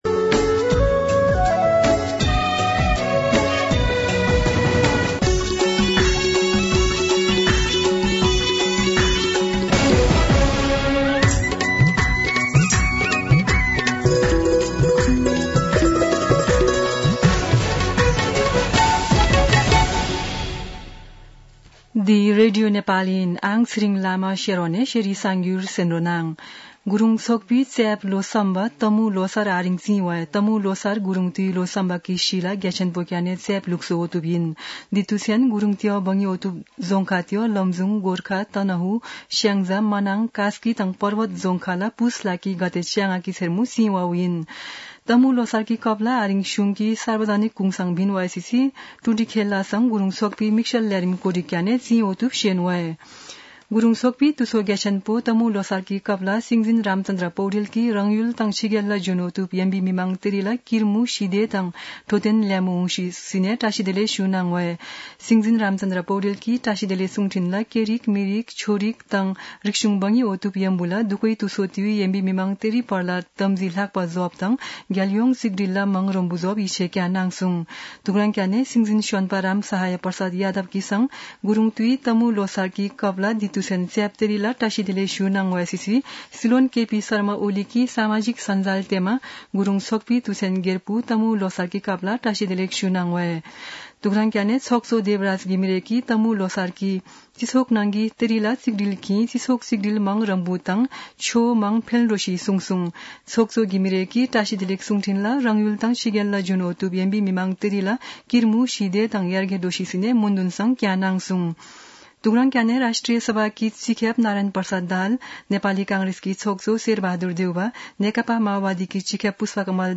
शेर्पा भाषाको समाचार : १६ पुष , २०८१
Sherpa-News-09-15.mp3